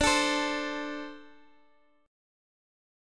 D57 chord {x x 12 14 13 x} chord